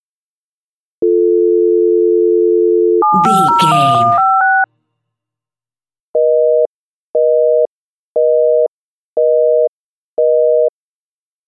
Telephone tone redial 7 numbers busy
Sound Effects
phone